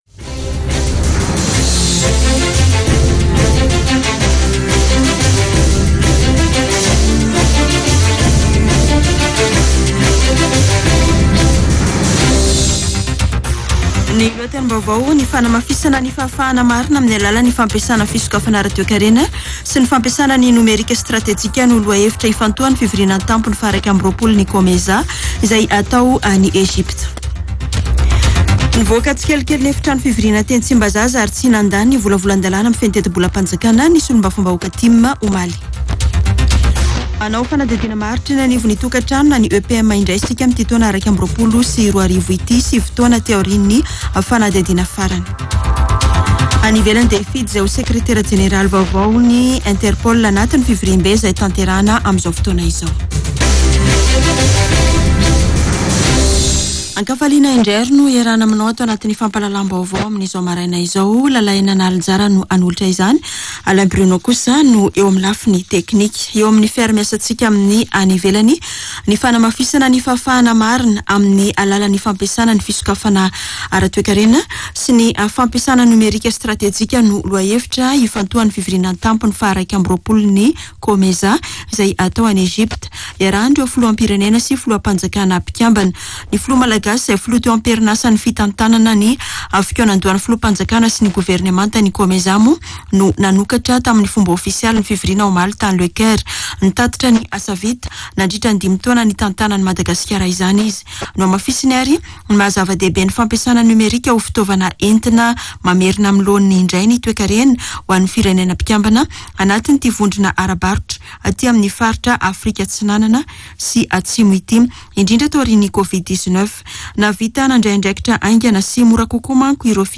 [Vaovao maraina] Alarobia 24 novambra 2021